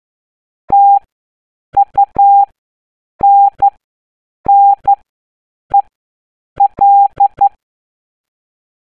Ascolta attentamente il codice Morse e risolvi l’indovinello di pagina 63.
MorseCode.mp3